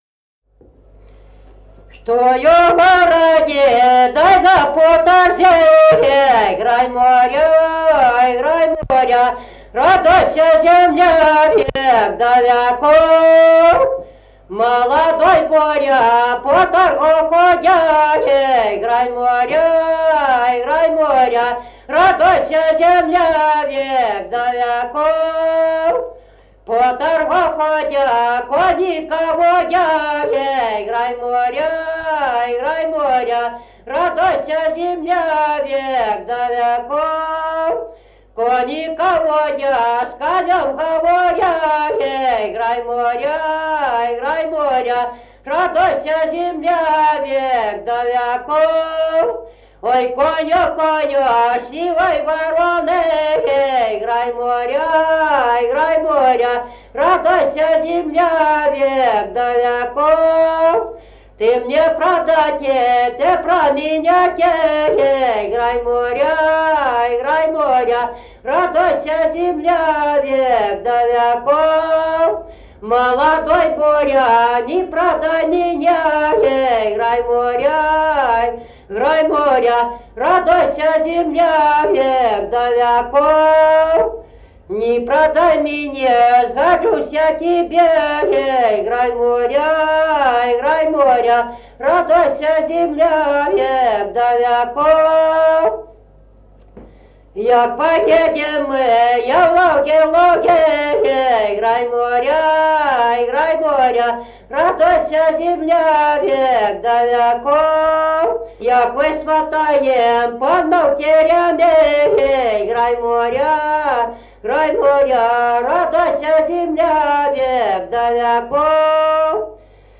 Народные песни Стародубского района «Чтой у городе», новогодняя щедровная.
1953 г., д. Камень.